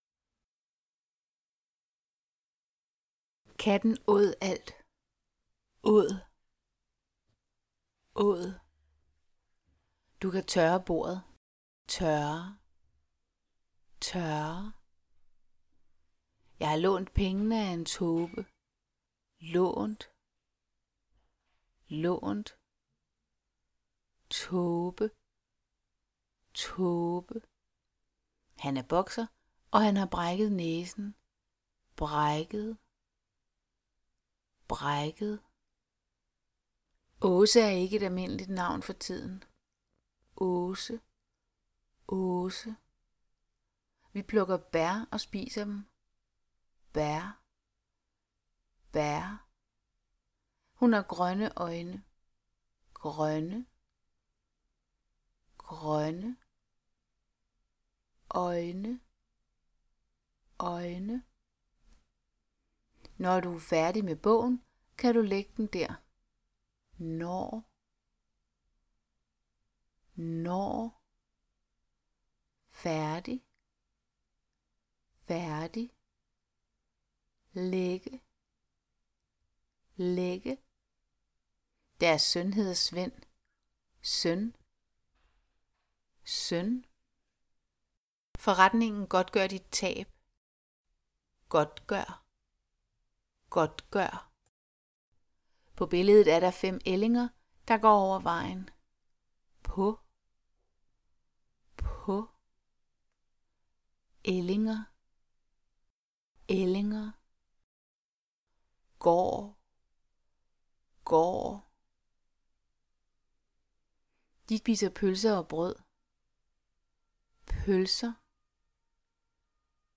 Diktat med æ, ø og å
æ-ø-og-å-diktat.mp3